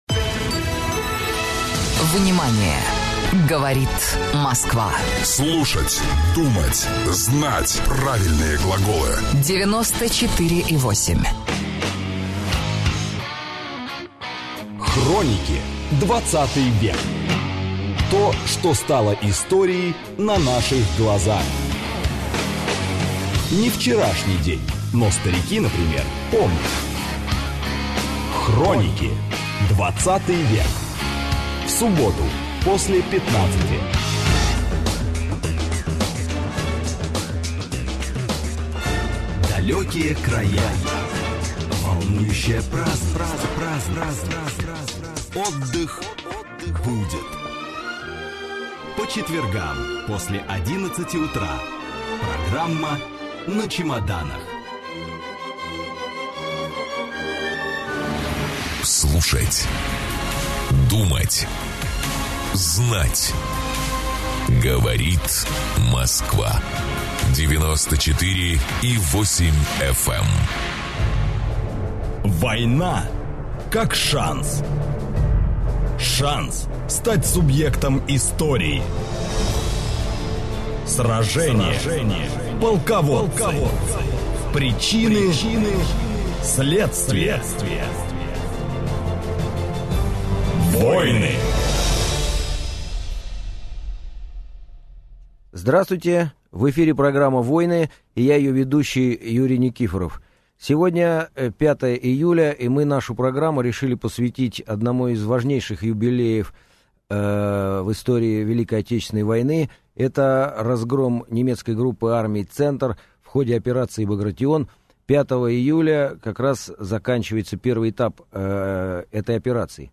Аудиокнига Полтавская битва | Библиотека аудиокниг